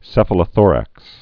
(sĕfə-lə-thôrăks)